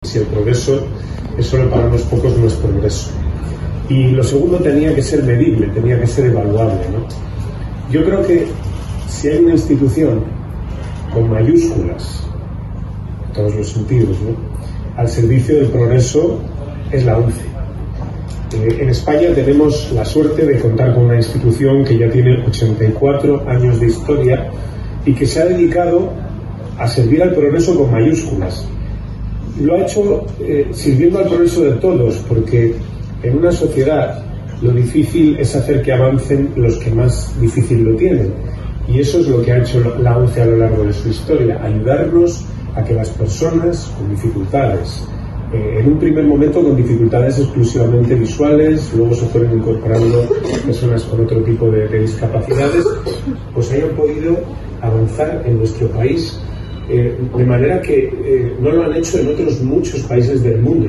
El pasado 3 de octubre se produjo el acto de colocación de la primera piedra de un nuevo gran edificio que se convertirá en el mayor centro de atención a la discapacidad de toda la región.
dijo el alcalde de Valladolid formato MP3 audio(1,16 MB).